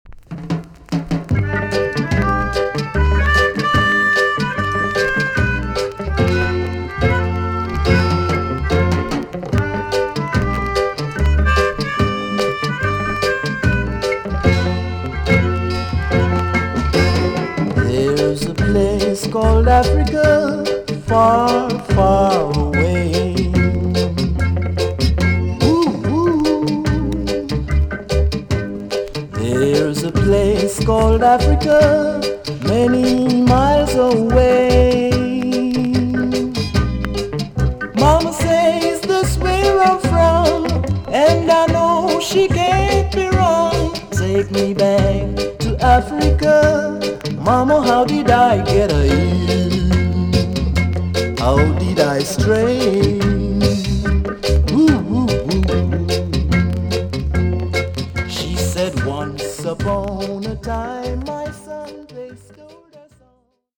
TOP >REGGAE & ROOTS
VG+ 少し軽いチリノイズがあります。